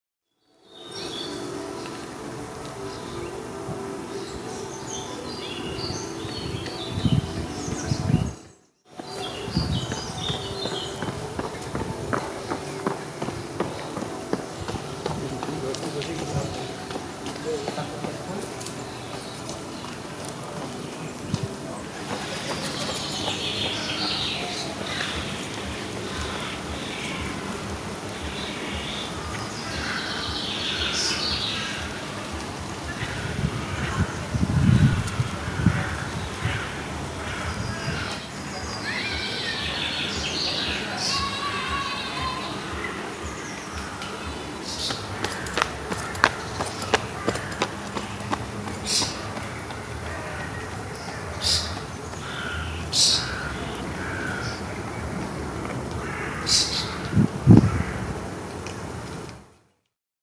Hör mal hin! 1 Minute Sommerstimmung rund um das Schloss Rosenstein